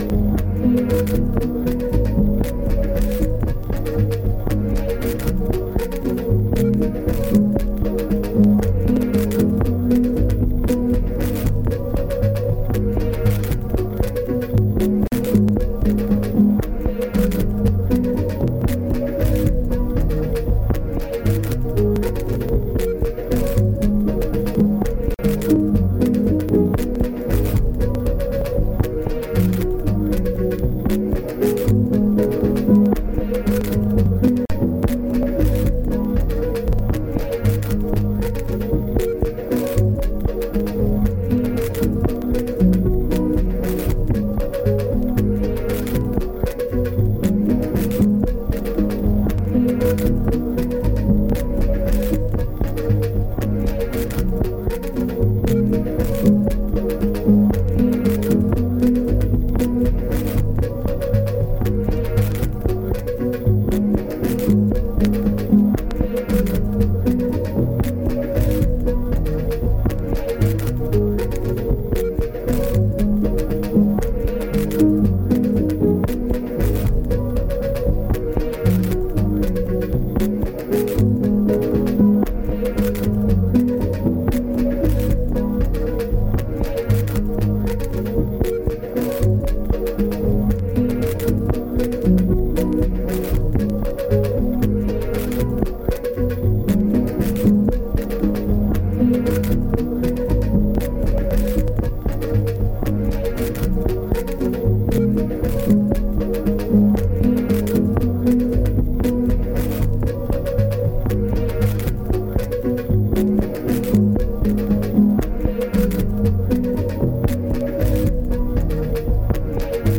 Sem samples, 100% produzido de raiz